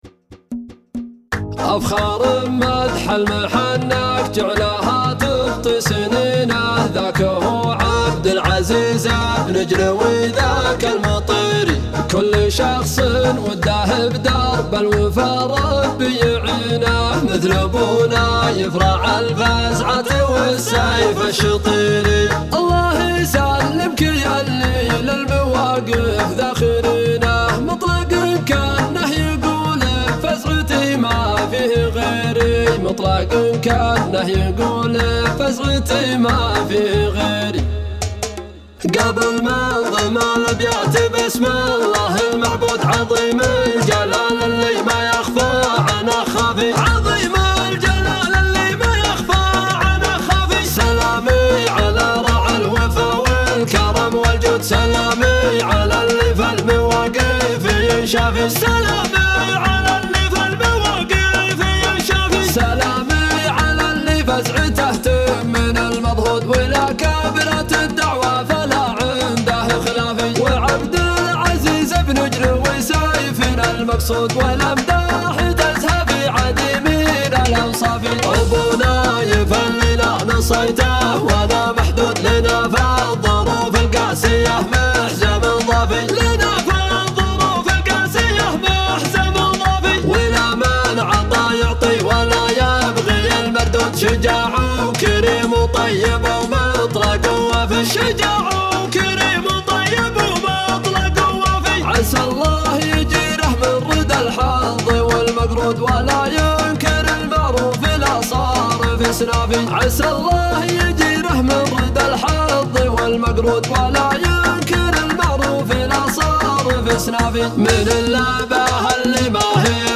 مناحي